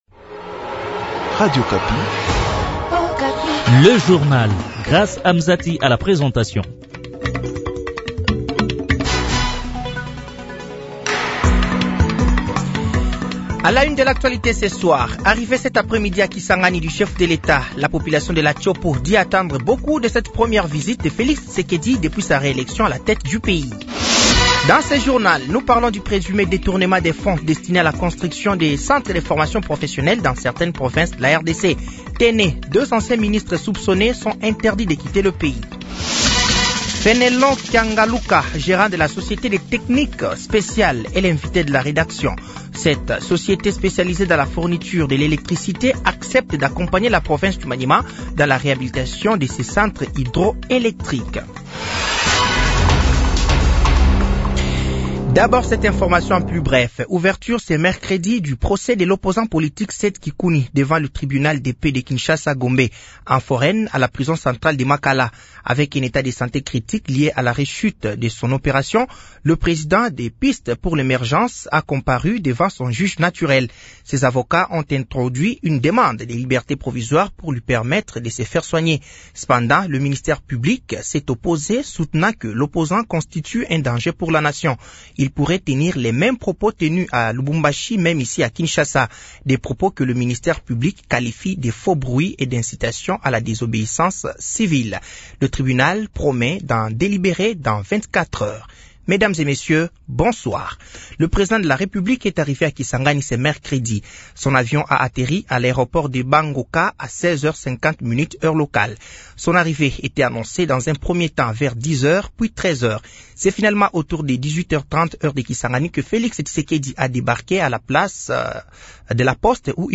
Journal français de 18h de ce mercredi 23 octobre 24